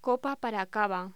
Locución: Copa para cava
voz